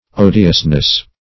odiousness - definition of odiousness - synonyms, pronunciation, spelling from Free Dictionary